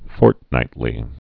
(fôrtnītlē)